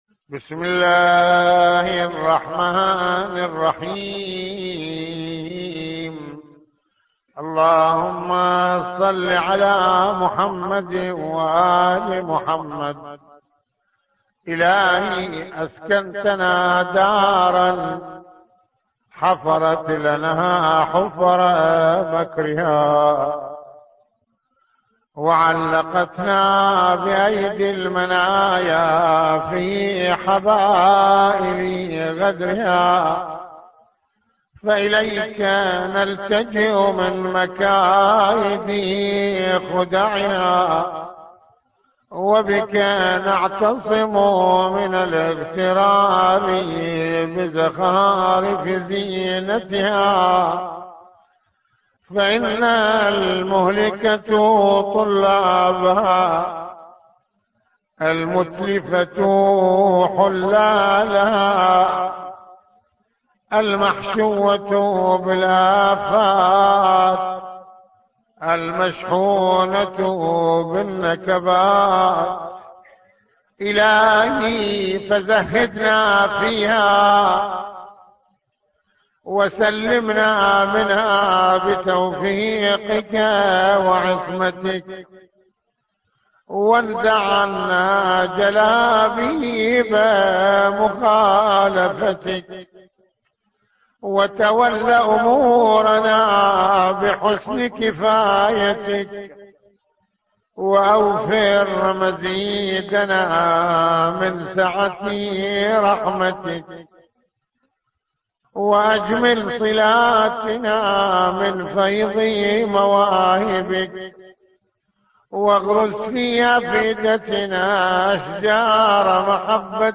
- استمع للدعاء بصوت سماحته